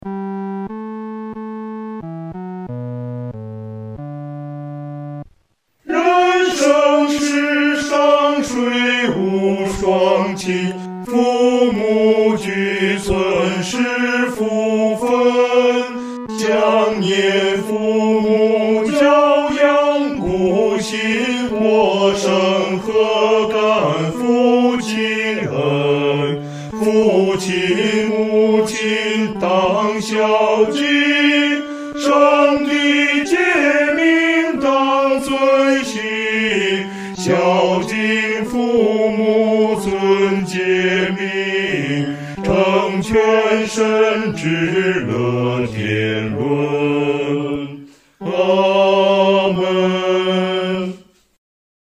女低